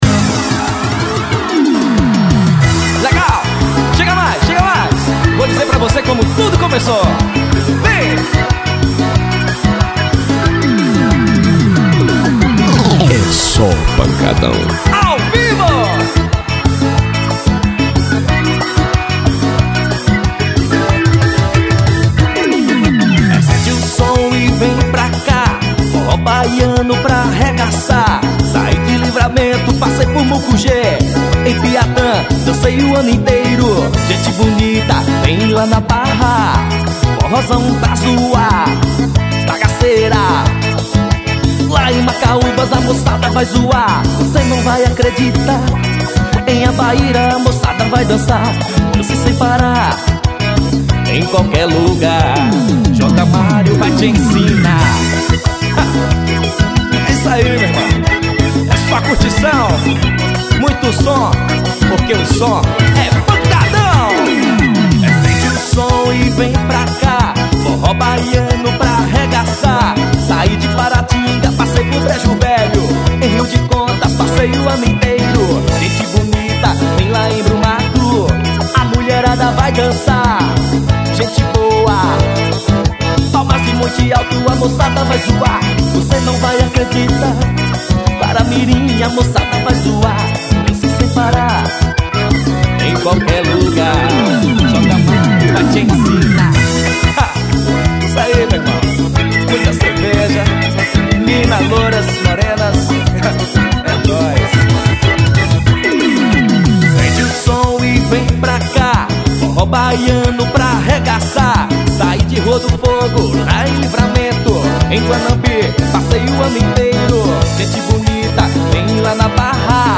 EstiloForró